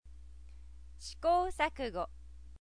Click for sound22k 試行錯誤 shi kou saku go